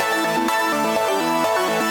SaS_Arp05_125-A.wav